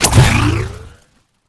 sci-fi_shield_power_on_impact_02.wav